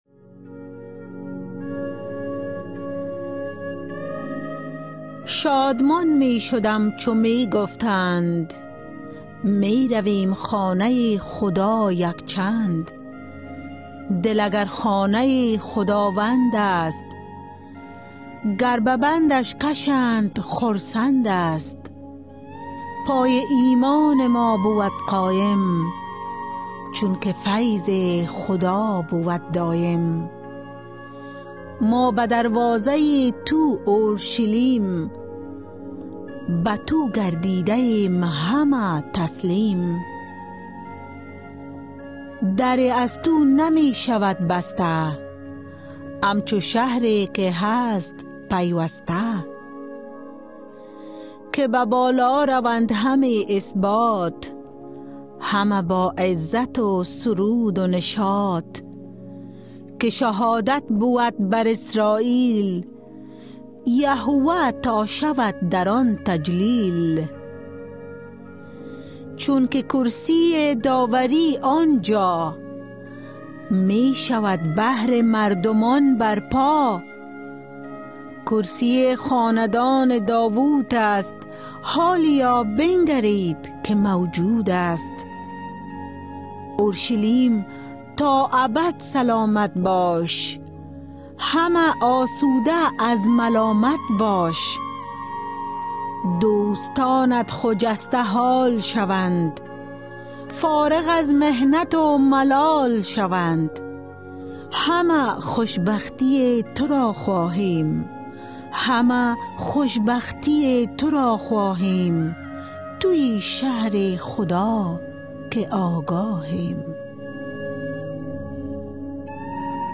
Poem Psalm 122